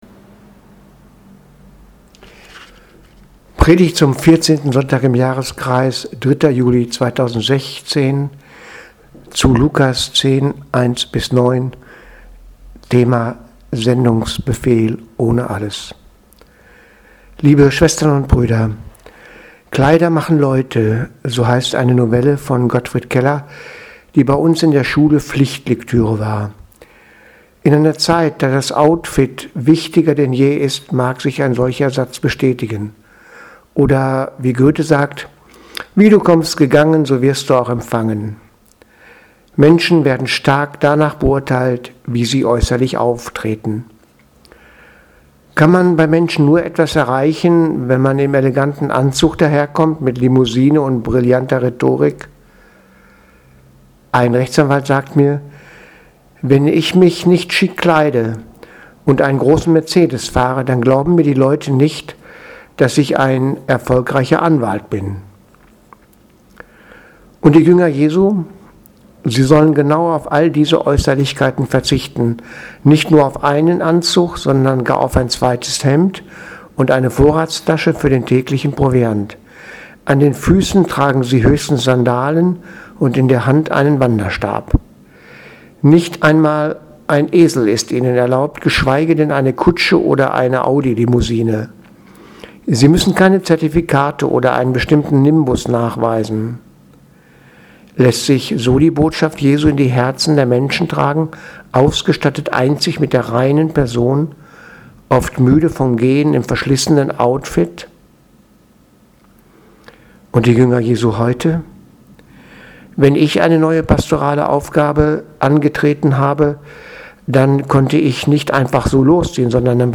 Heutige Predigt nur als mp3 verfügbar.